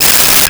Cell Phone Ring 11
Cell Phone Ring 11.wav